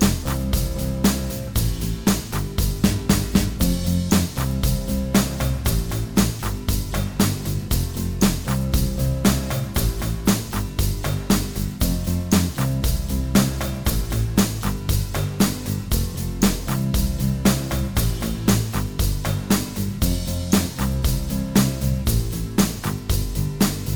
Minus Main Guitar Pop (1980s) 4:25 Buy £1.50